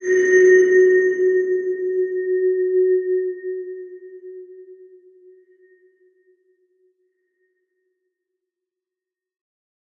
X_BasicBells-F#2-pp.wav